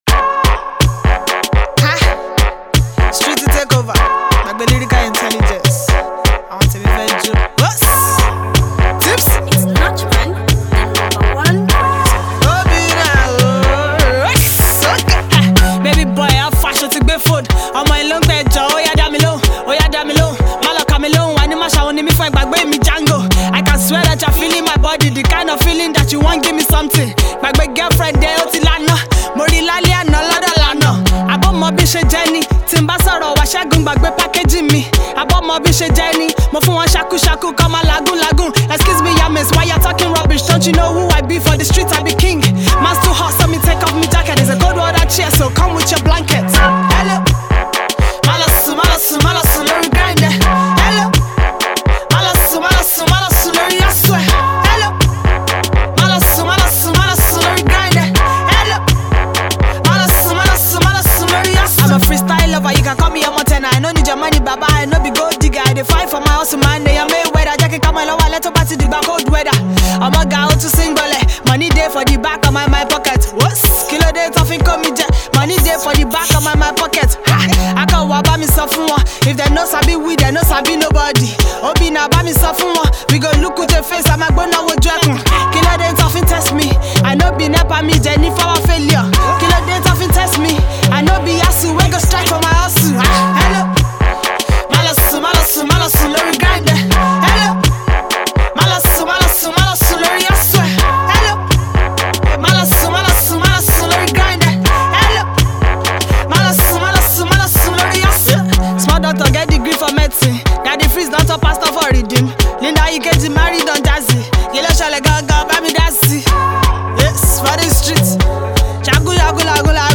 Nigerian Female Rapper
The new street tune